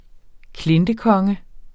klintekonge substantiv, fælleskøn Bøjning -n, -r, -rne Udtale [ ˈklendəˌ- ] Betydninger 1. mytisk bjergmand der ifølge folketroen boede i og herskede over Møns eller Stevns Klint Klintekongen er en gammeldags konge.